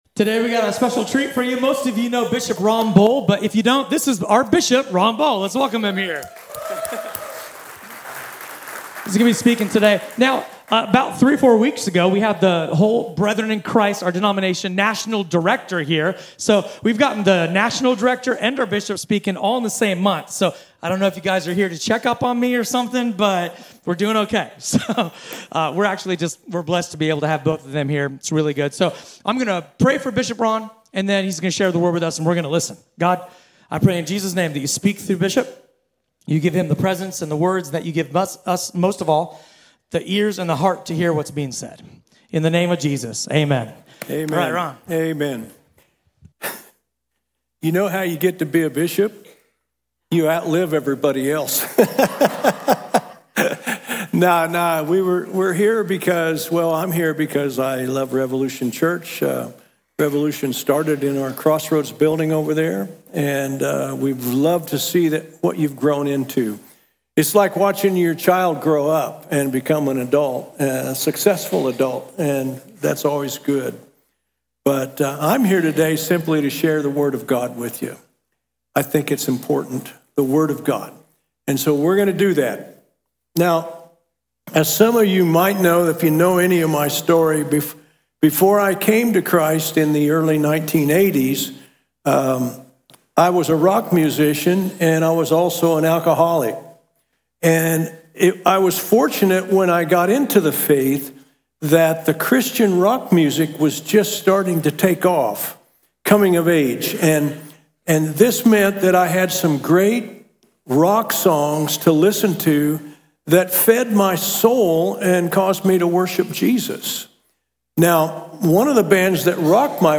A sermon from the series "Guest." Have you ever wondered how ancient poetry connects to Jesus' story?